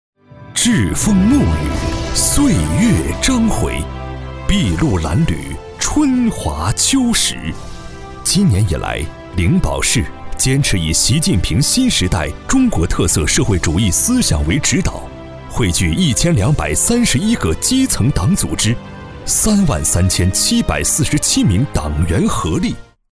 100%人工配，价格公道，配音业务欢迎联系：
A男198号
【专题】大气党政宣传片.mp3